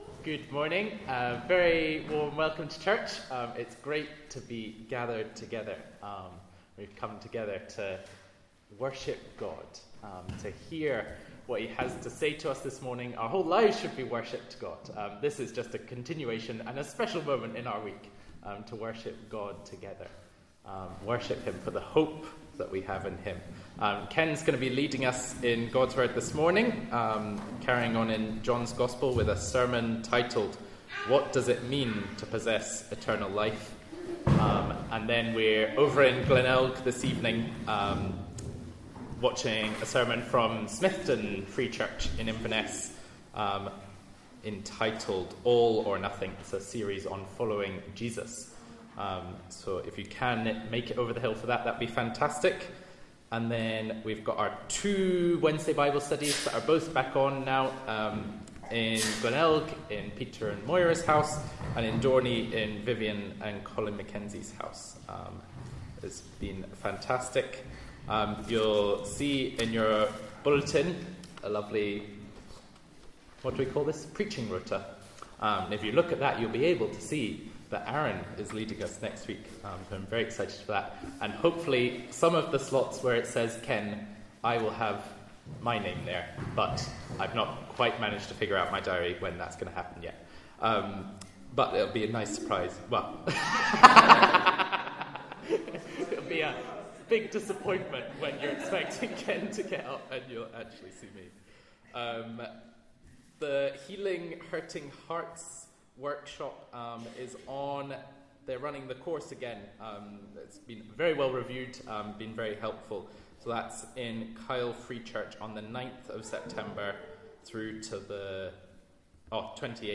Passage: John 17:1-5 Service Type: Inverinate AM